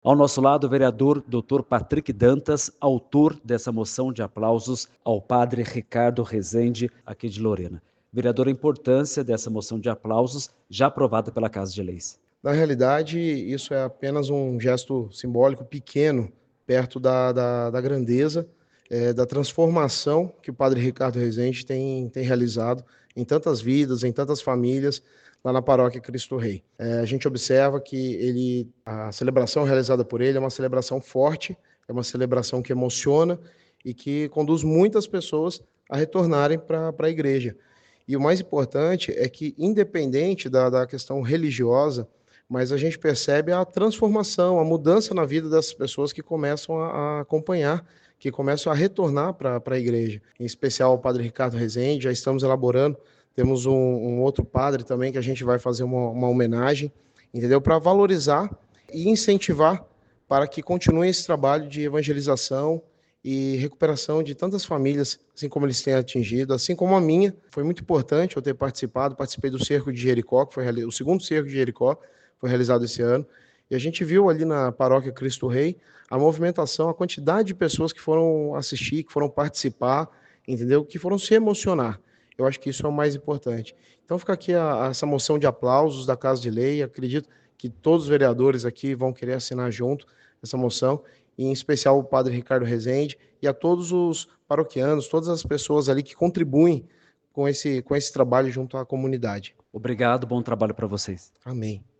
Entrevistas (áudios):